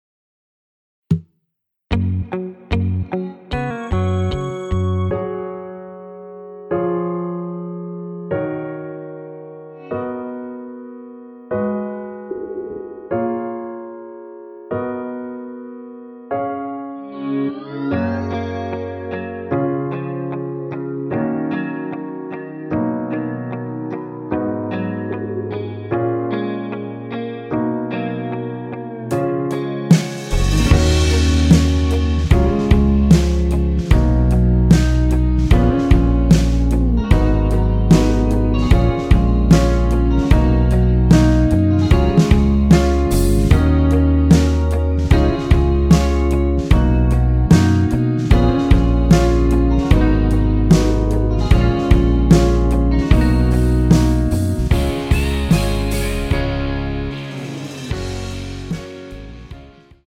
Gb
앞부분30초, 뒷부분30초씩 편집해서 올려 드리고 있습니다.
중간에 음이 끈어지고 다시 나오는 이유는